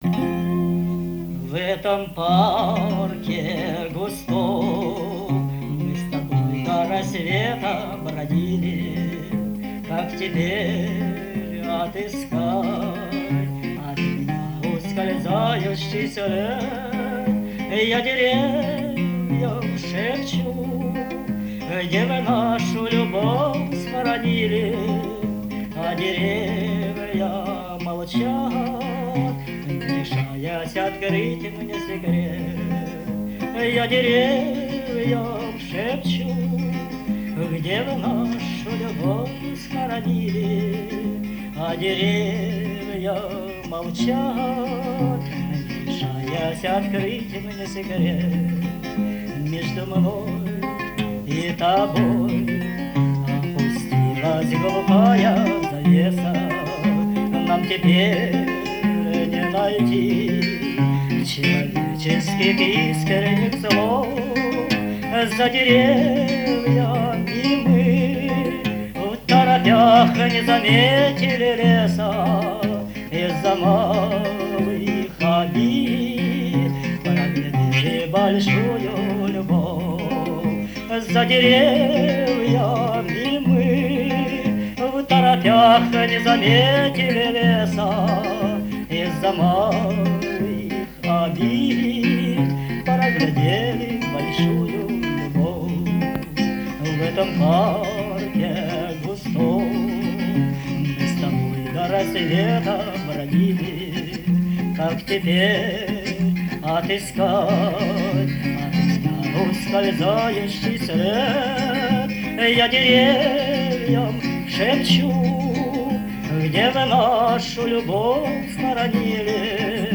Красивейшее исполнение и голос!
Кто-то из современных...голосок дрожит, гитарка ...не нравится.